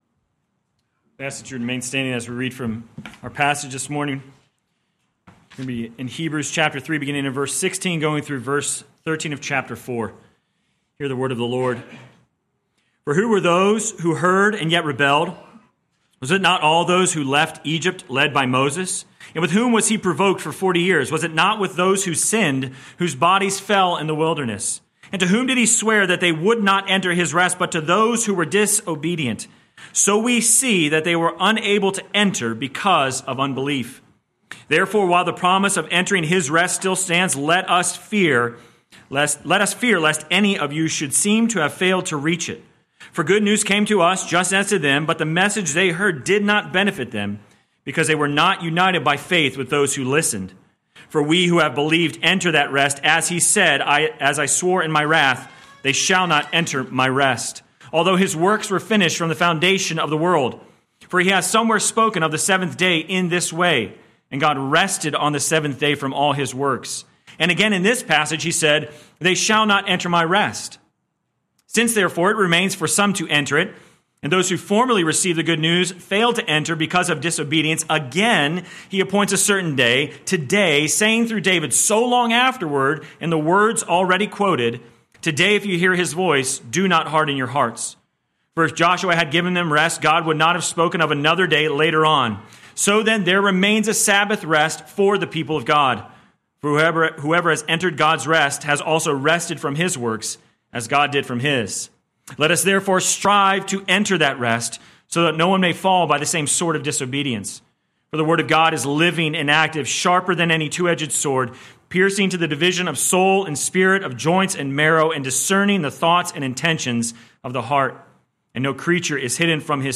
Sermon Text: Hebrews 3:16-4:13 First Reading: Genesis 2:1-3 Second Reading: Matthew 11:25-12:8